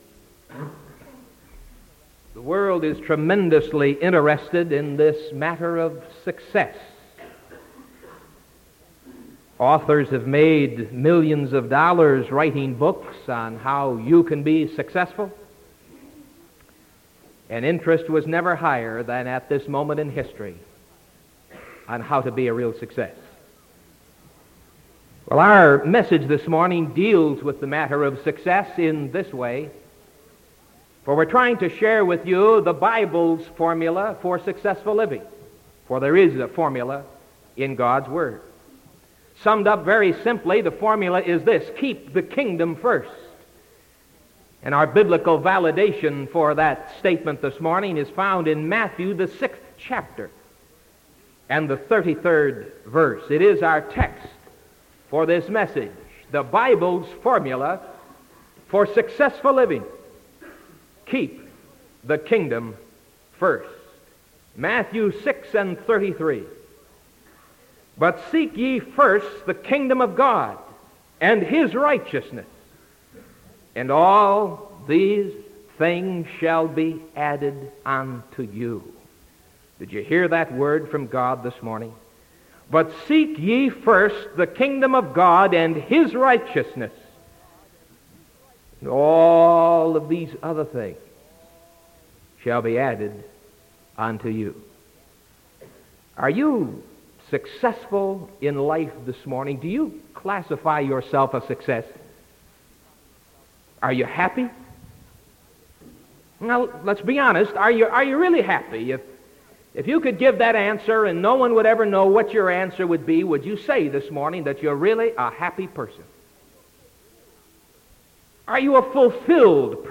Sermon February 9th 1975 AM